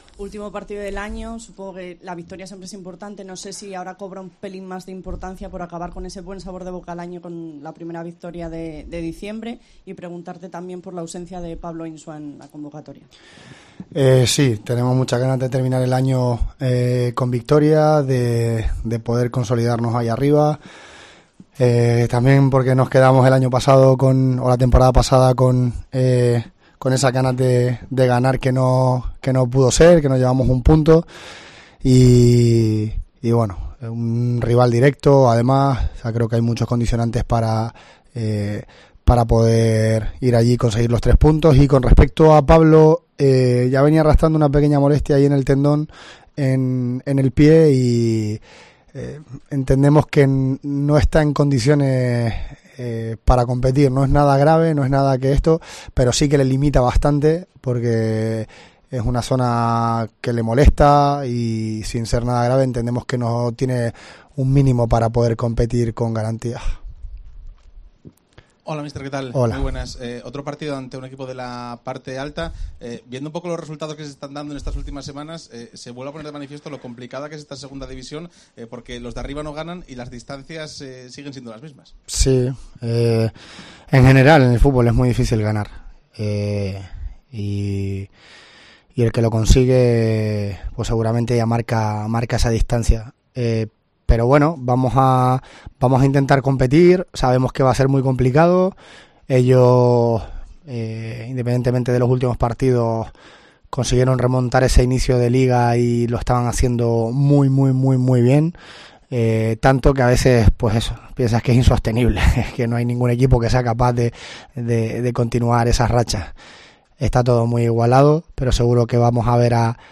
Convocatoria y rueda de prensa